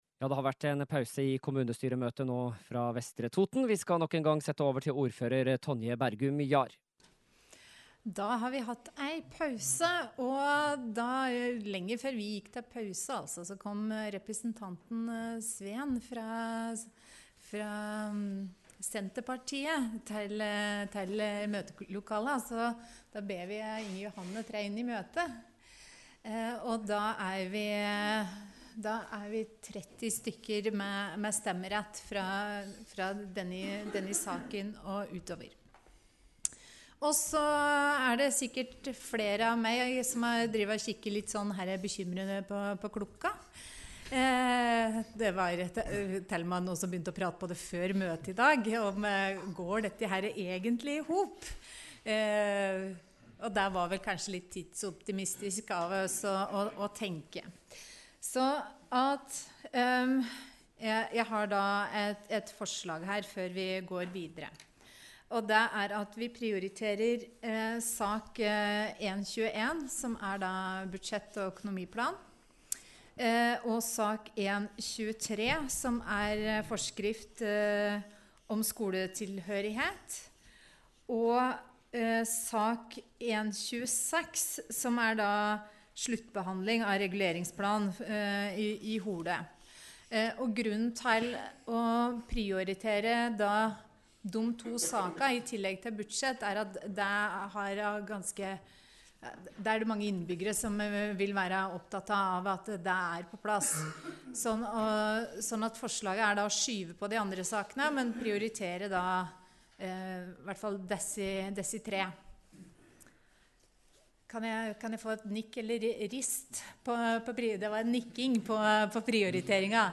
Kommunestyremøte fra Vestre Toten 14. desember – Lydfiler Lagt Ut | Radio Toten